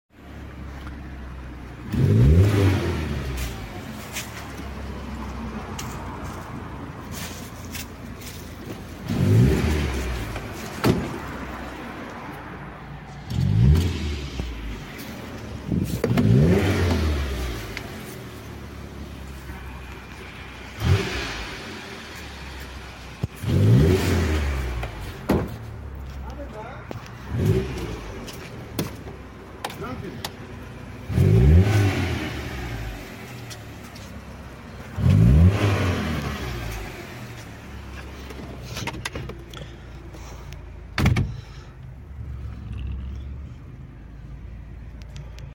Fiat Tipo Kapı Pandizot sound effects free download